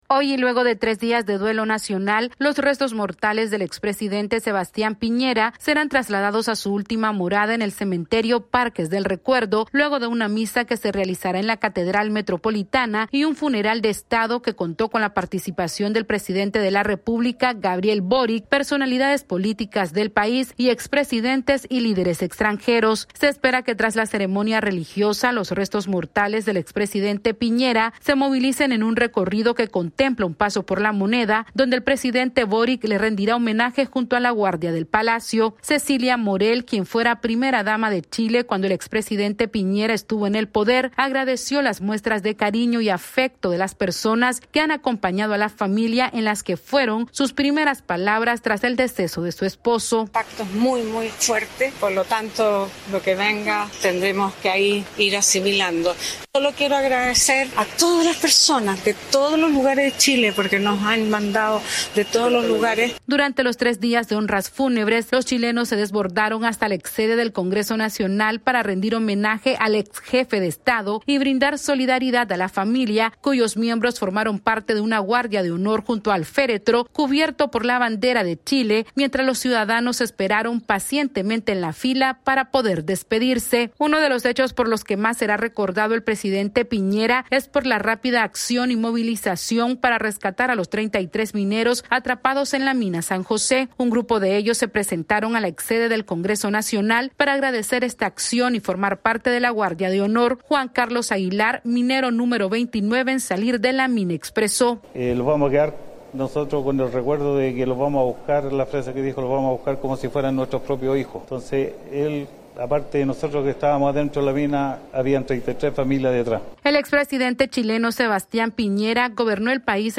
AudioNoticias
Chile despide hoy al expresidente Sebastián Piñera con un funeral de Estado luego de tres días de duelo nacional y contará con la presencia de expresidentes y líderes extranjeros. Esta es una actualización de nuestra Sala de Redacción...